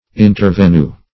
Search Result for " intervenue" : The Collaborative International Dictionary of English v.0.48: Intervenue \In`ter*ven"ue\, n. [See Intervene , Avenue .]